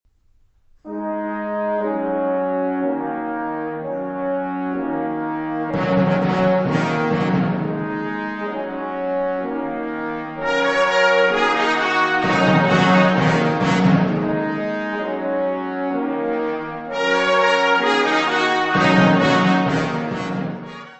Music Category/Genre:  Classical Music
I Allegretto.